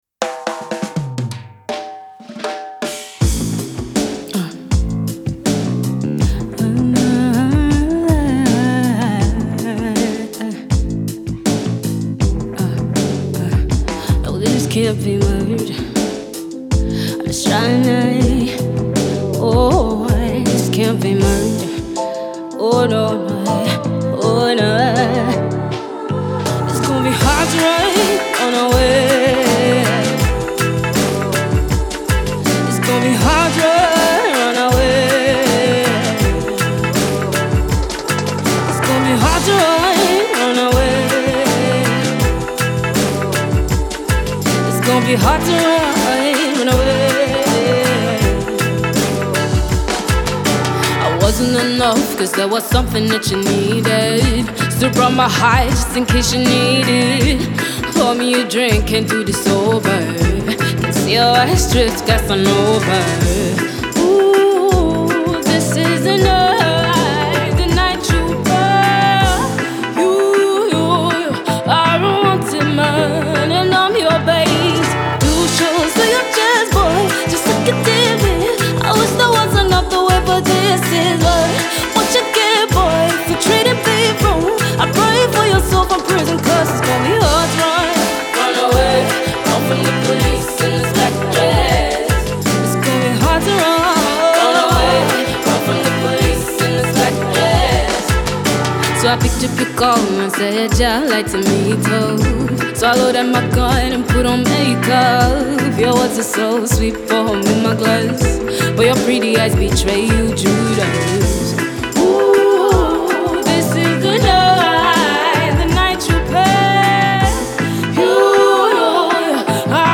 neo-reggae fusion